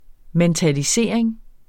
Udtale [ mεntaliˈseˀɐ̯eŋ ]